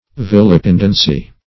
Search Result for " vilipendency" : The Collaborative International Dictionary of English v.0.48: Vilipendency \Vil"i*pend"en*cy\, n. Disesteem; slight; disparagement.